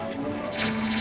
Added violin